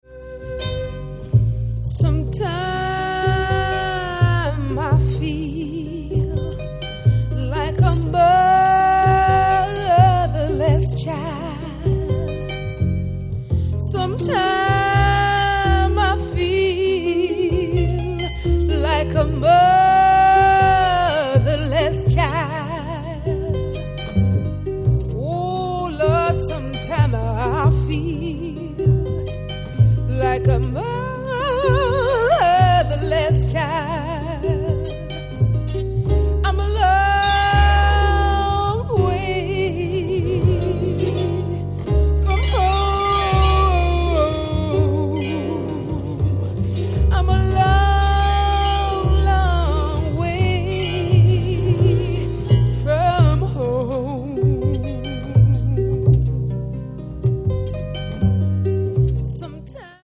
Spiritual Jazz & Soul